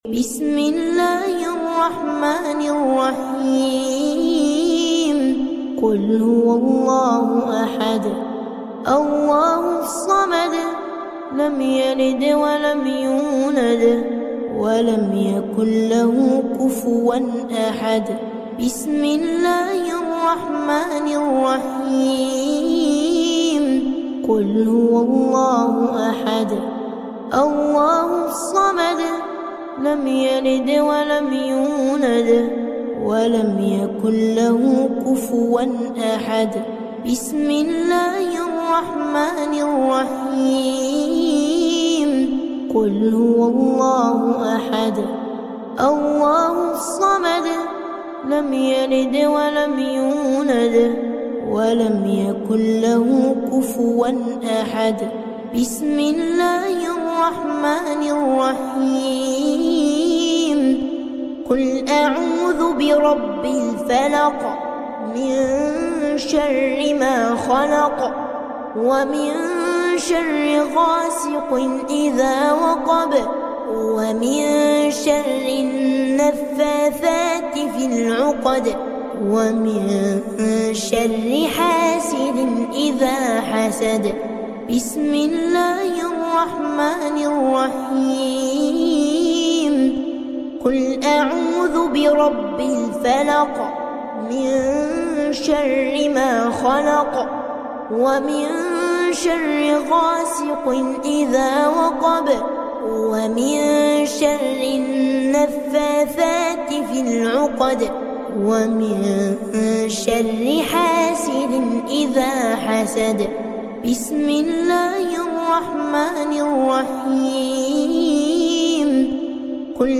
Beautiful Quran Recitation